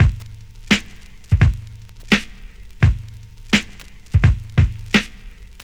• 85 Bpm 2000s Drum Beat A Key.wav
Free drum beat - kick tuned to the A note. Loudest frequency: 951Hz